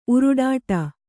♪ uruḍāṭa